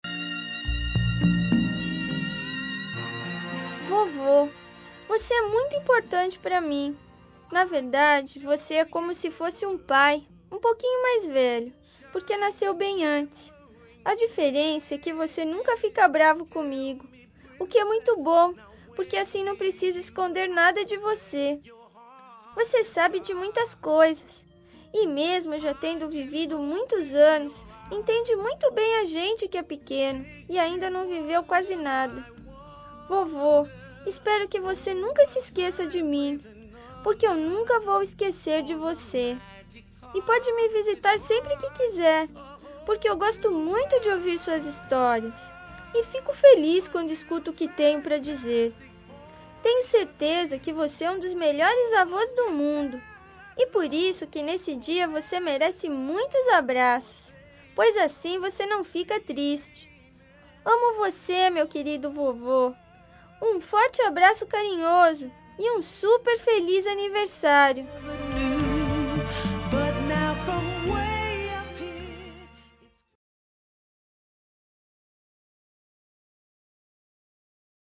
Aniversário Voz Infantil – Avô – Voz Masculina – Cód: 257565